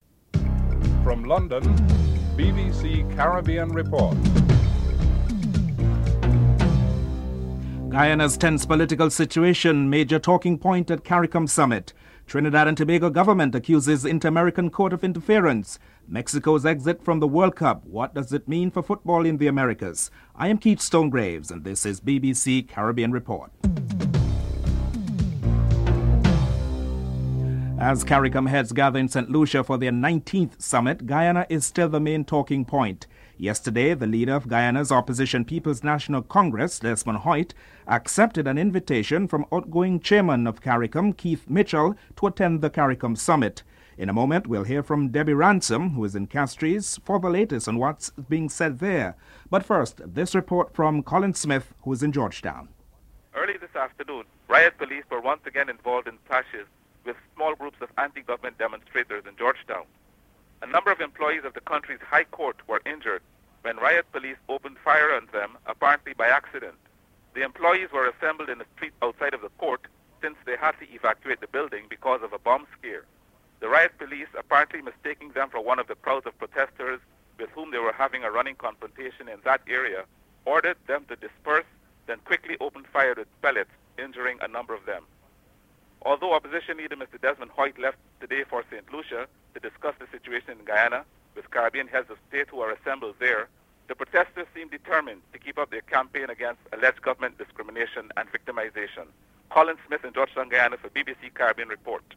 8. Recap of top stories (14:51-15:10)